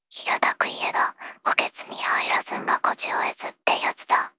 voicevox-voice-corpus
voicevox-voice-corpus / ROHAN-corpus /ナースロボ＿タイプＴ_内緒話 /ROHAN4600_0018.wav